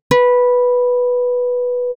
5.1. 押弦
また、1次固有振動数は492.6Hzであり、音程の定義値493.9Hzに近い値になっていました。